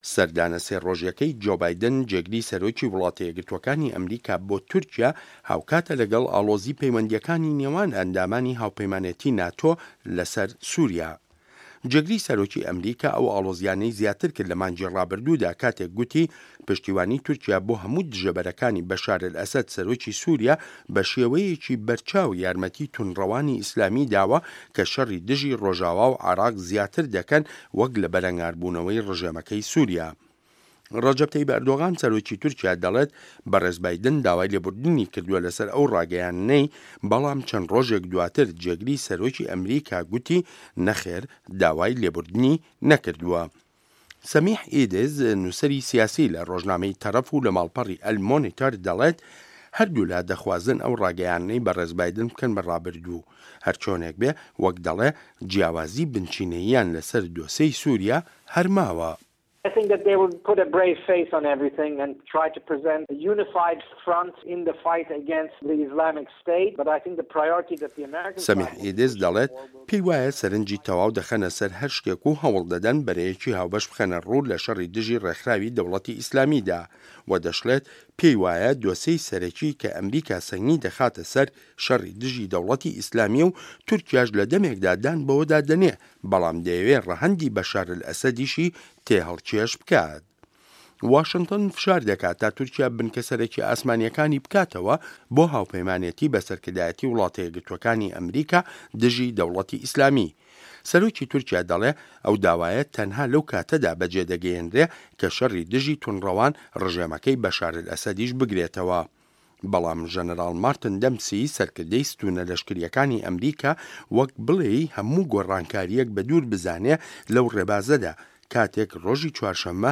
ڕاپـۆرتی سه‌ردانی جۆ بایدن بۆ تورکیا